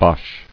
[bosh]